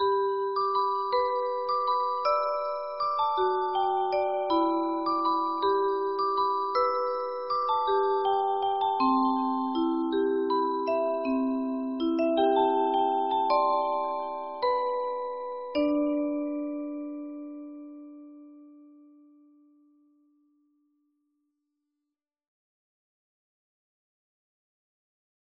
-Music Box Melodies,